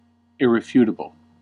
Ääntäminen
IPA : /ˌɪrɪˈfjuːtəbl/